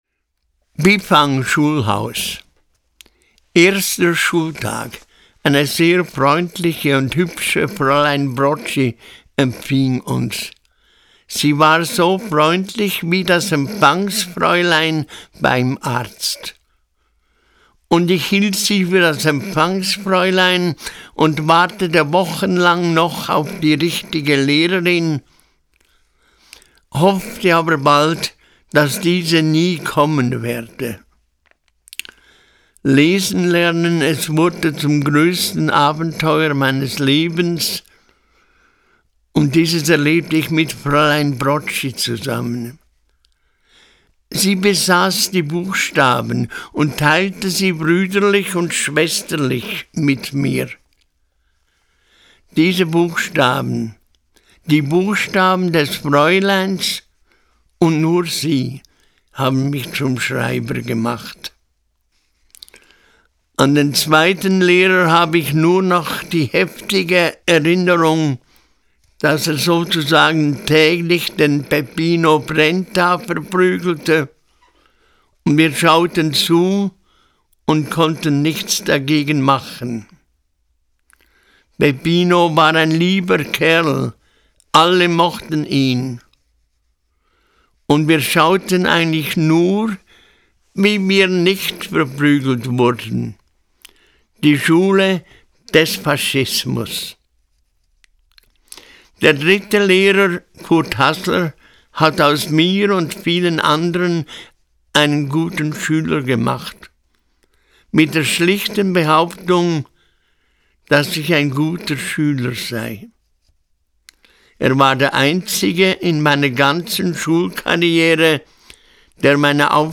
So ist seine Stimme auf dem Schriftstellerweg weiterhin zu hören – zum Beispiel hier: